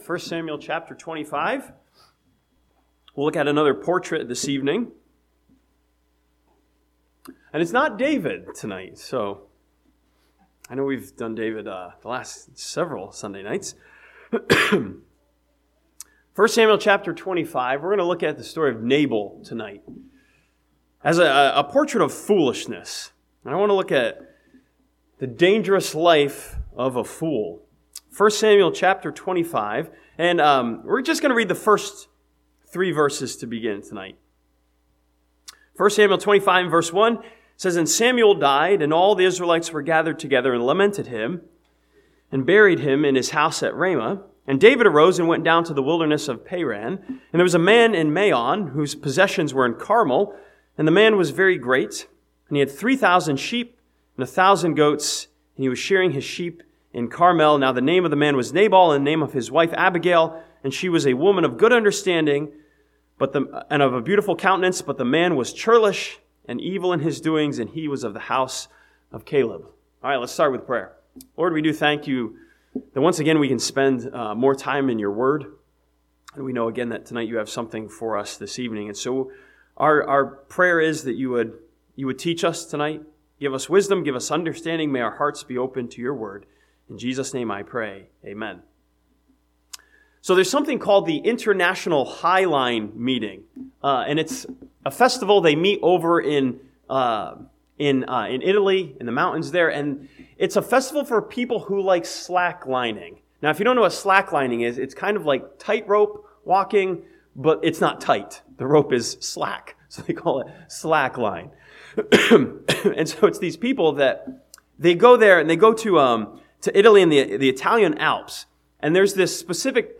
This sermon from 1 Samuel chapter 25 studies Nabal the fool as a portrait of foolishness and compares him with the book of Proverbs.